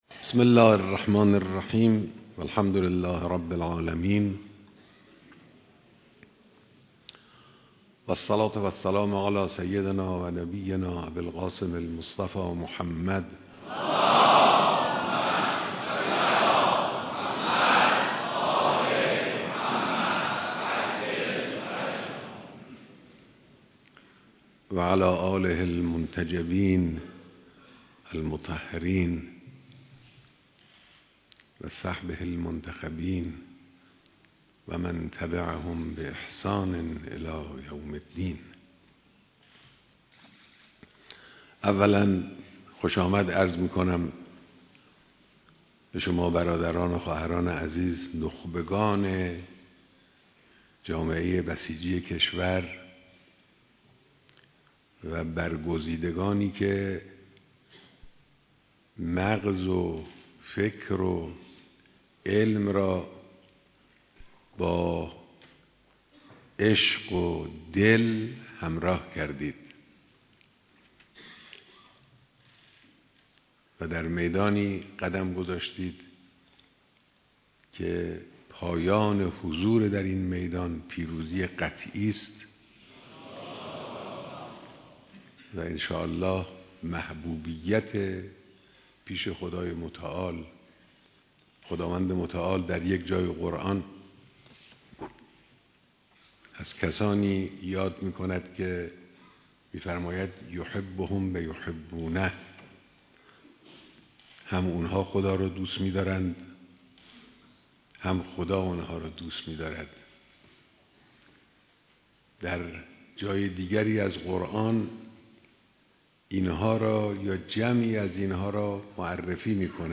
بیانات در دیدار اعضای مجمع عالی بسیج و نمایندگان اقشار مختلف بسیج